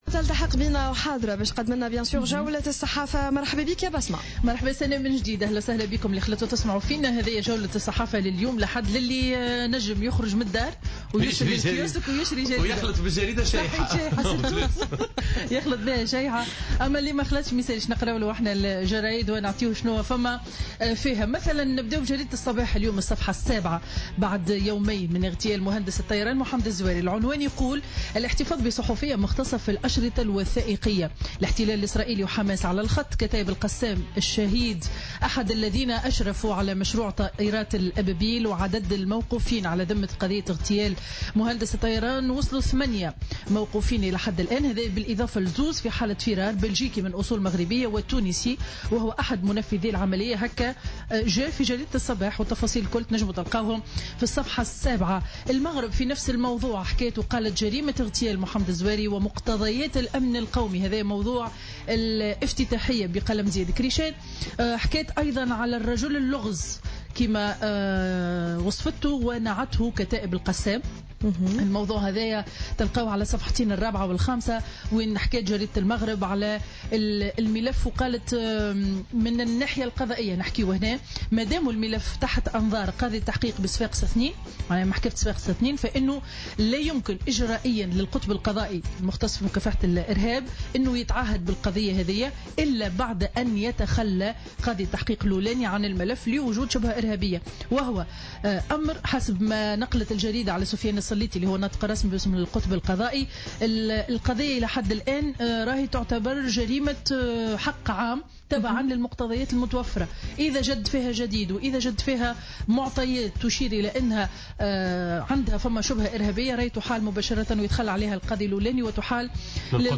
Revue de presse 18/12/2016 à 08:28